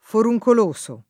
DOP: Dizionario di Ortografia e Pronunzia della lingua italiana
foruncoloso